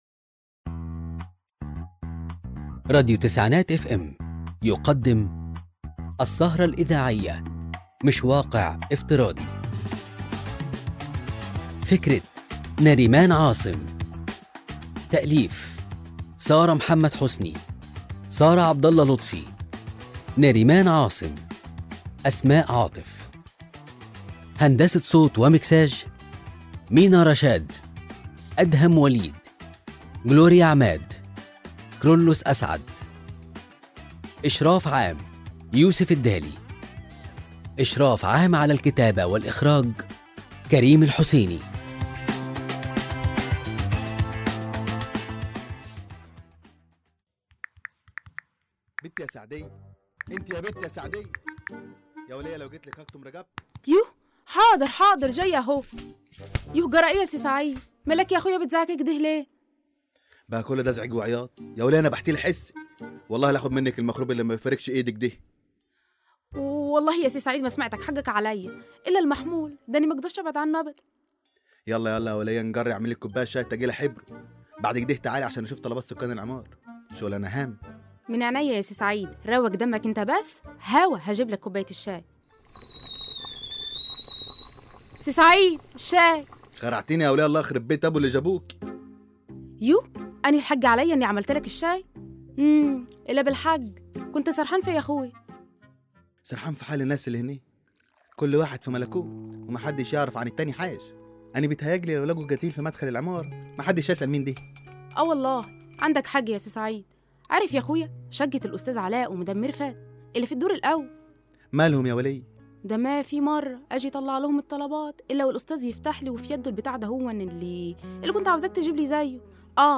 الاسكتش الإذاعي مش واقع افتراضي